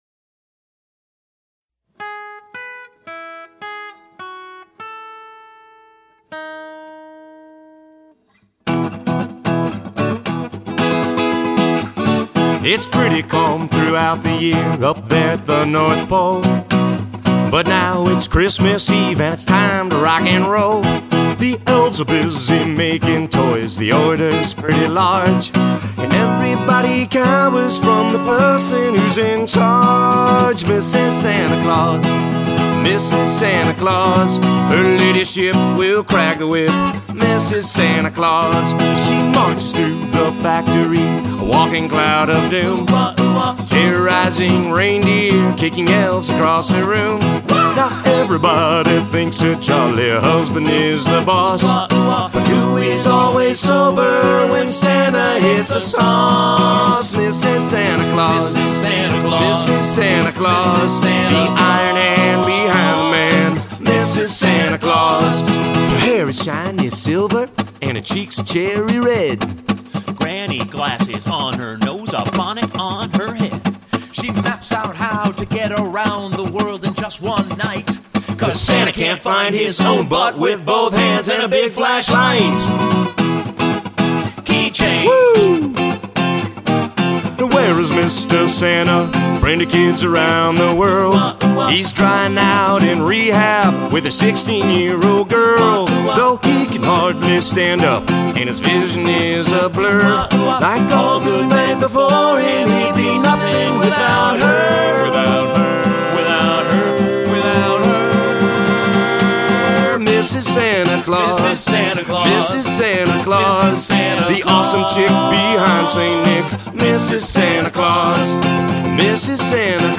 Rehearsal Audio